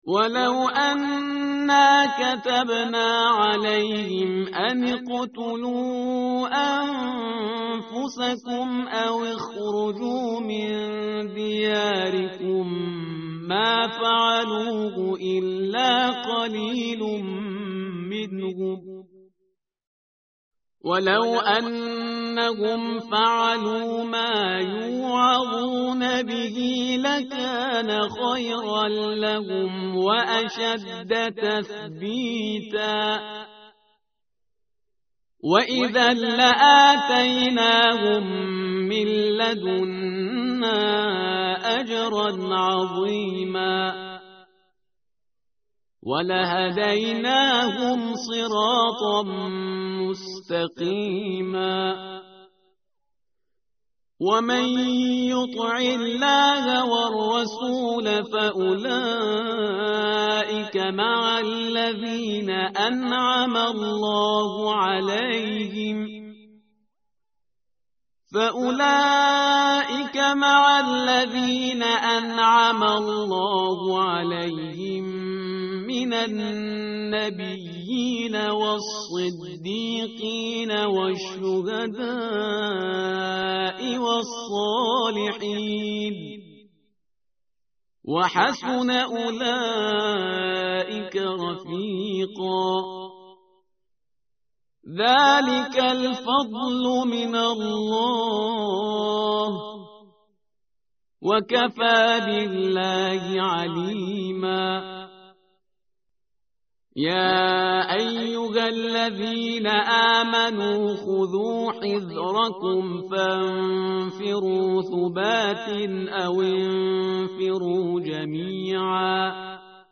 tartil_parhizgar_page_089.mp3